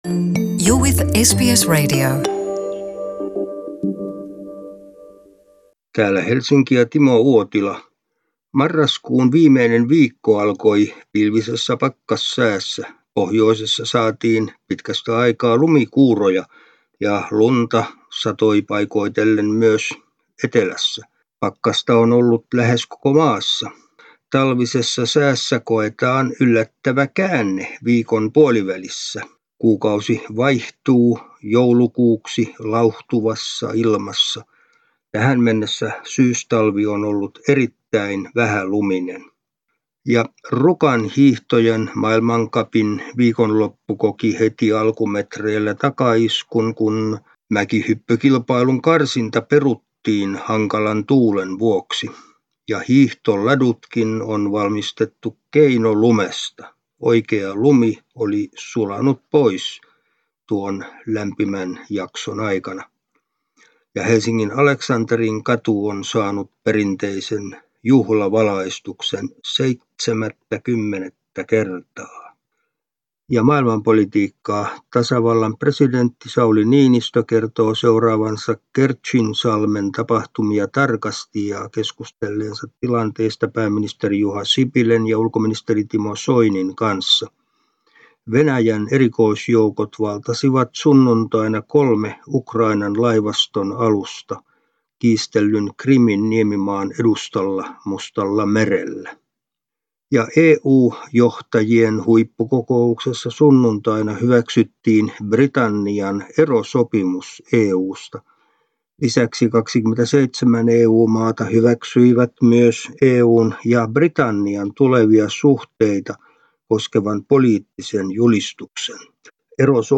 ajakohtaisraportti